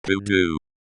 It should be noted that the stress in pudu is on the final syllable; in English, that would be /
This pronunciation is from Castilian pudú.
pudu-en.opus